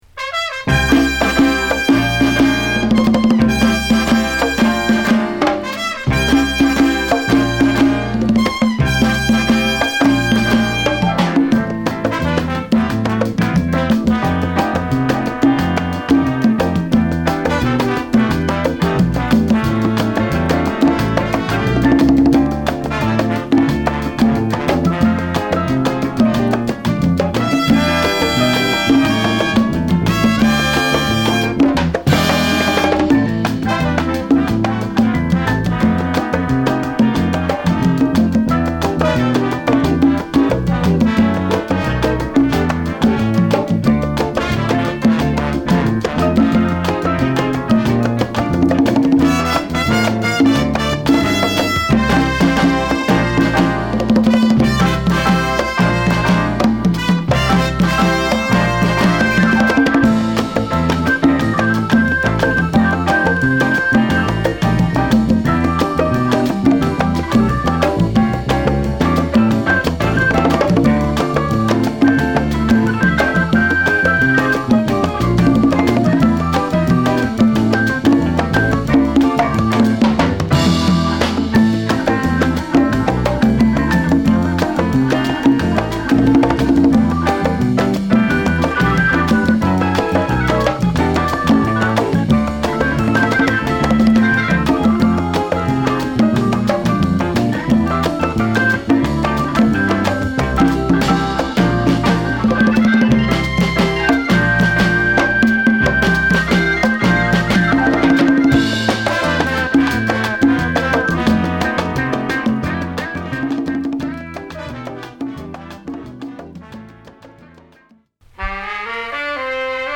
ファンキーパーカッション乱れ撃つカリビアン／レアグルーヴチューンを満載！